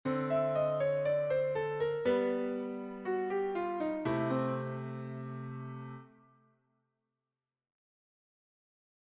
Improvisation Piano Jazz
L’ enclosure consiste à jouer des notes ( chromatiques ou pas ) ‘autour’ de la note visée ( target)